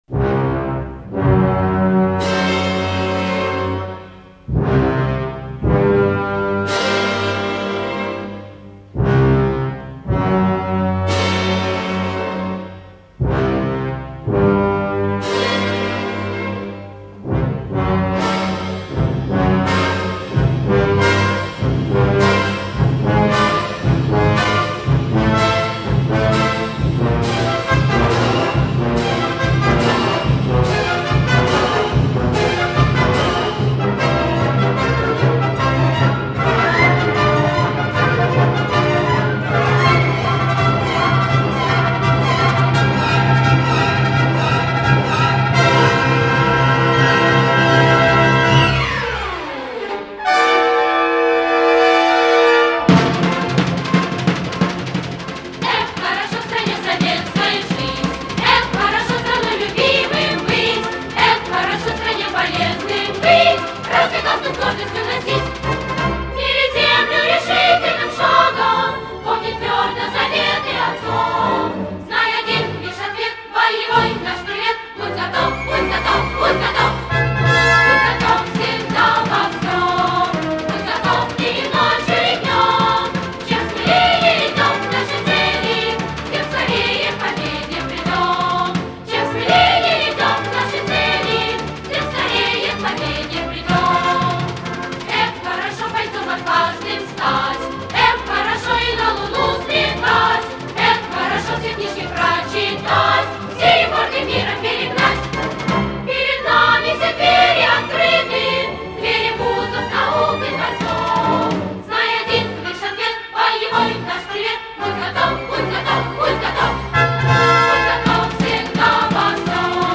Бодрая и радостная пионерская песня